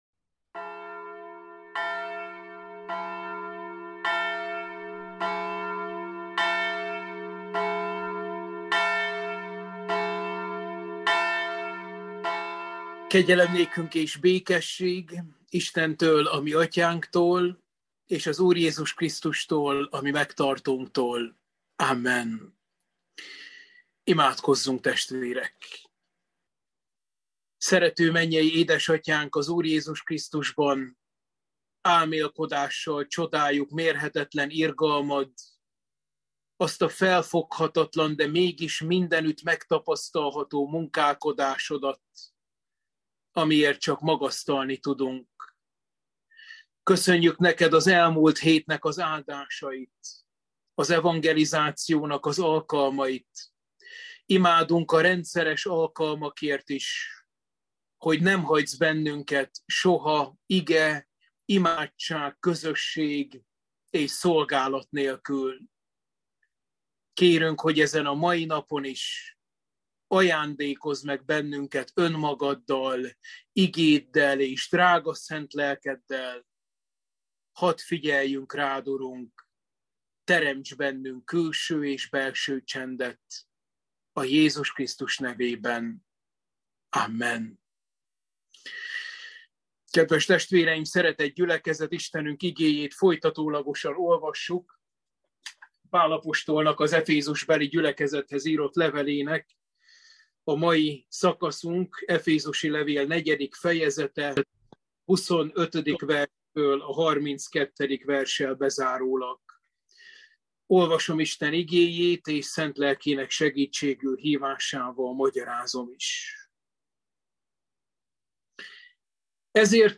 Efézusi levél – Bibliaóra 18